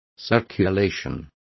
Complete with pronunciation of the translation of circulations.